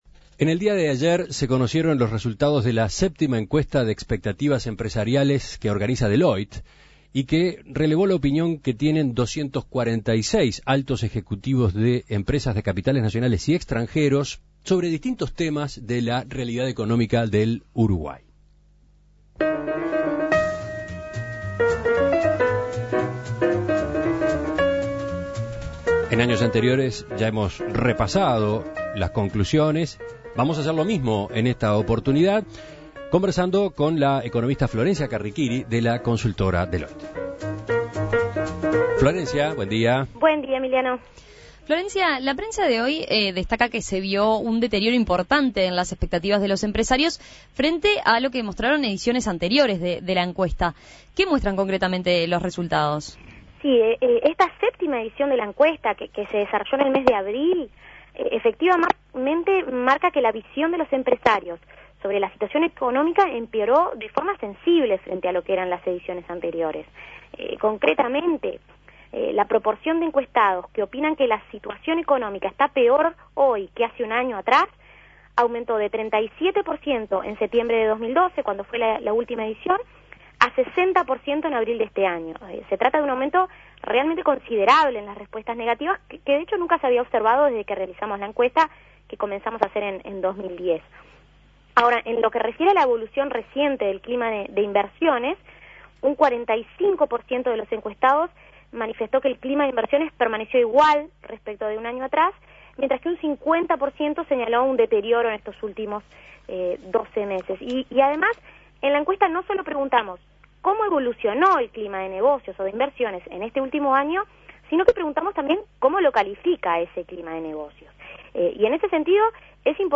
Análisis Económico Las expectativas empresariales en Uruguay